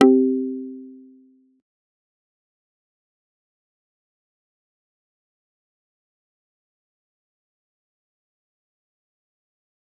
G_Kalimba-C4-pp.wav